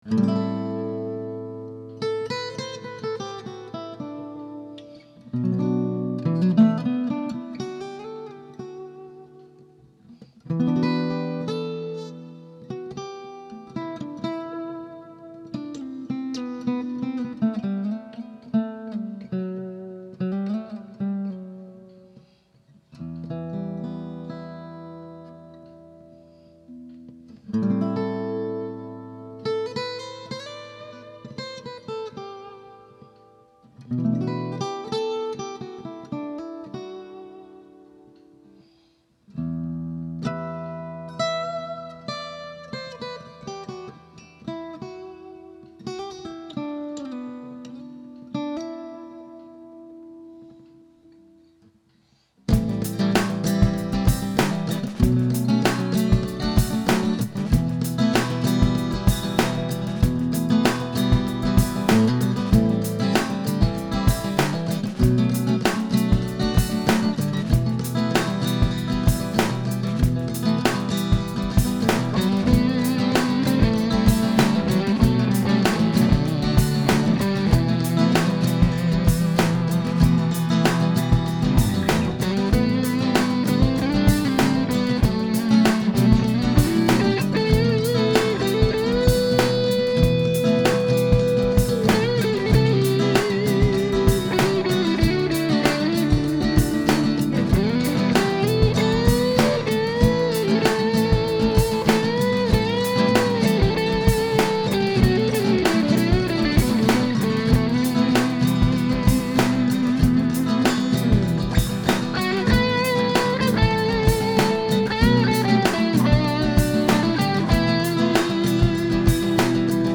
I put together some quick clips to demonstrate the sound of this guitar (all clips were played through my beloved Aracom PLX18 “plexi” clone):
Finally, I quickly played a lead over a song idea I’m working on. The first part is played in the neck pickup, then I switch to the bridge to show the difference. VERY Les Paul-like in both response and dynamics: